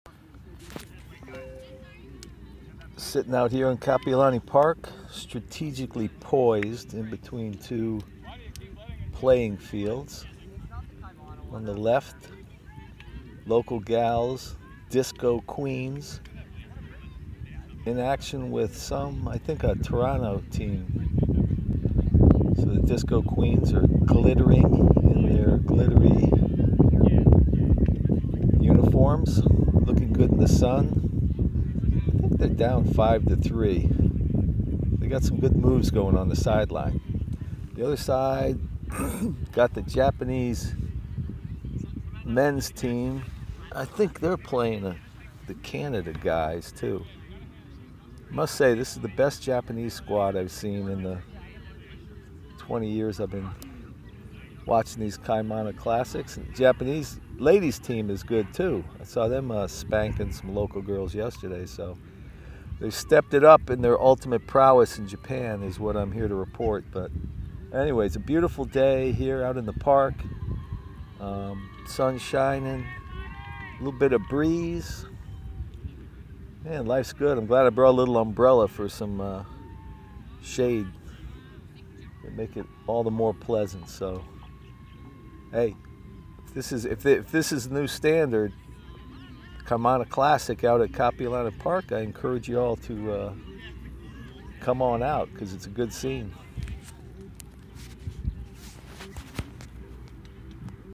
Then I settled down in a strategic position to view two games simultaneously.  I was right between two games that featured local teams, The Killer Clowns in the Open Division, and the Disco Queens in the Women’s Division.
game-watching.mp3